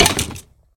骷髅：受伤
受伤时随机播放这些音效
Minecraft_Skeleton_hurt1.mp3